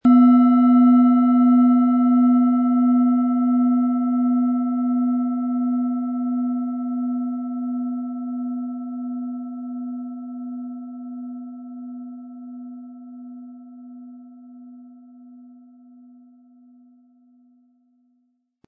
Antike Klangschale aus unserer „Schatzkammer“
Diese von Hand getriebene Klangschale besitzt einen warmen, tiefen Ton mit einem reichhaltigen Klangcharakter. Ihre Schwingungen sind sanft, aber durchdringend – sie berühren nicht nur das Gehör, sondern auch die Seele.
Um den Originalton der Schale anzuhören, gehen Sie bitte zu unserer Klangaufnahme unter dem Produktbild.
Der passende Schlegel ist umsonst dabei, er lässt die Schale voll und harmonisch tönen.
MaterialBronze